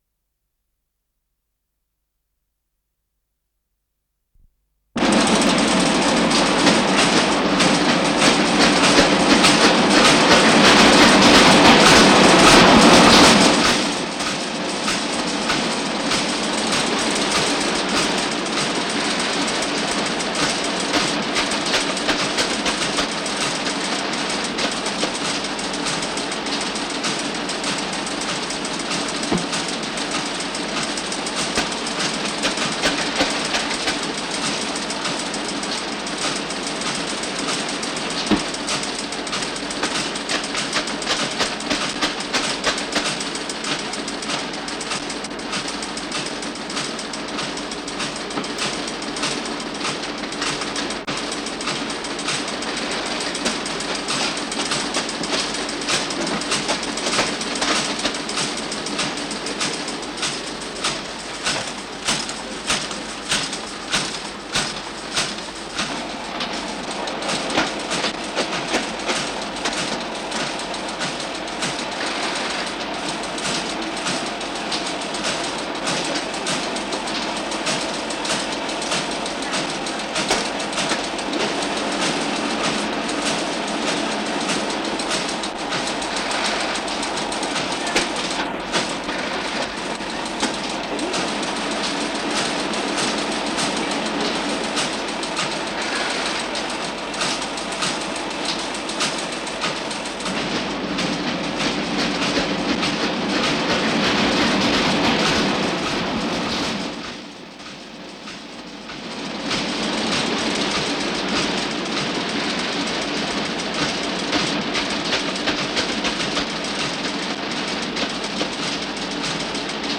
с профессиональной магнитной ленты
Название передачиМашинно-счётная станция, зал обработки перфокарты
РедакцияШумовая
ВариантМоно